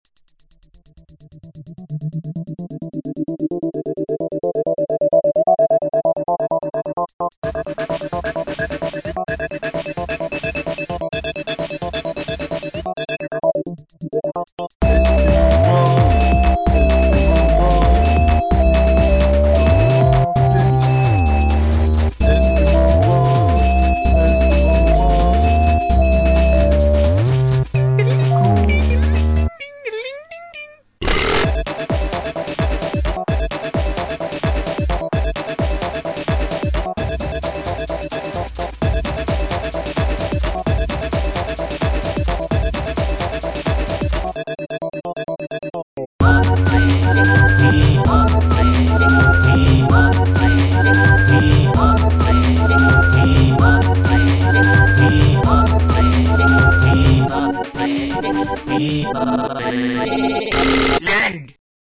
remixed